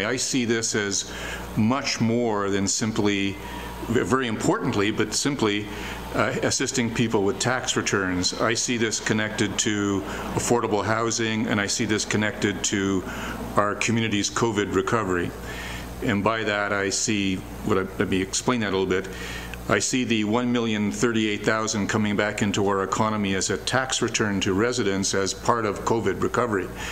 At Tuesday’s council meeting, Councillor Bill Roberts remarked that the money people received as tax returns with help from PELC is not only money back in people’s pockets, but money back into the community in a time of economic recovery due to the COVID-19 pandemic.